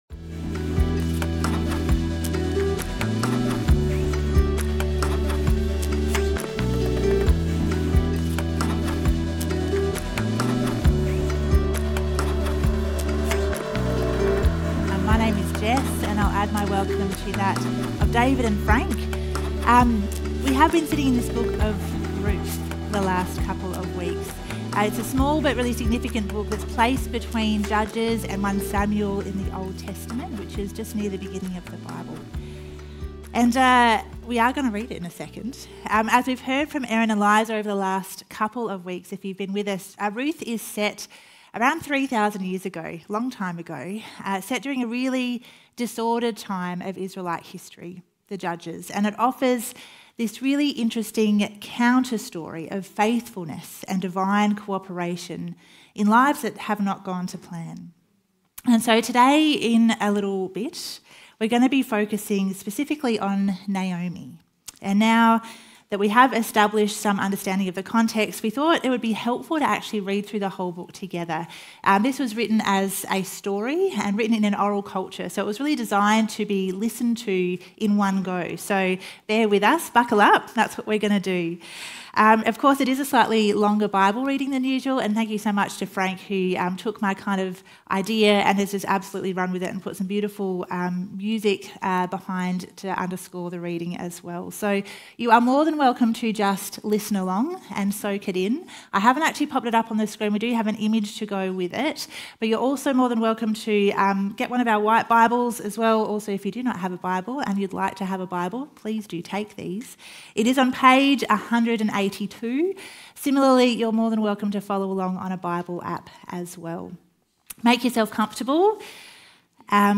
The sermon today centres around Naomi and her descent into grief because of her loss. God invites honest lament and this is not the end of Naomi’s story.